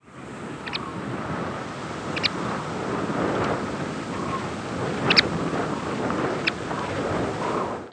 McCown's Longspur diurnal flight calls